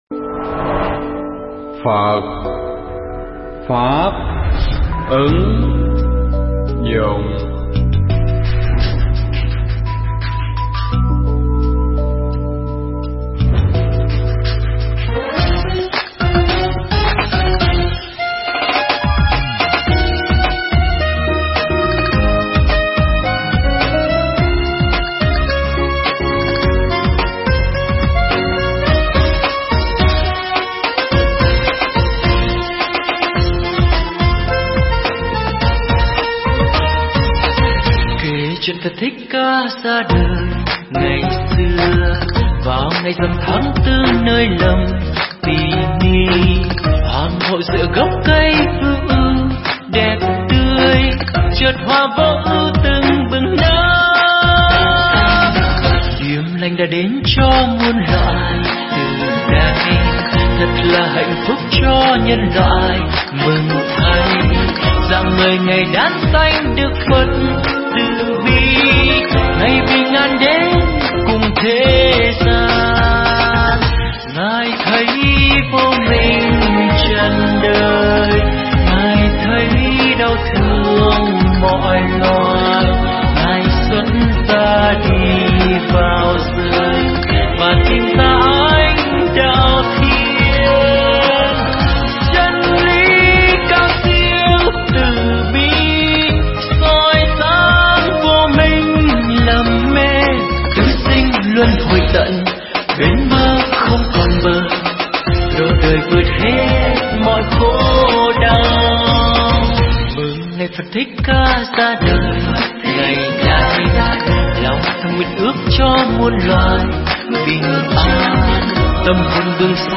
Mp3 pháp thoại Học Phật Để Làm Người Tốt Hơn
giảng tại Tu Viện Tường Vân (Bình Chánh, HCM)